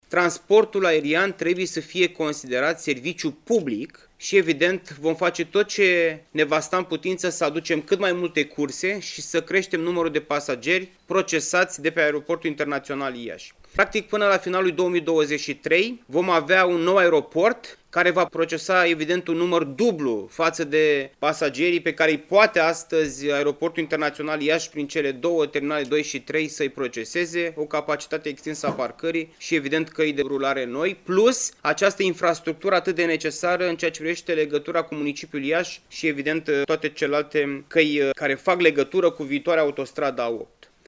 Președintele Consiliului Județean Iași, Costel Alexe: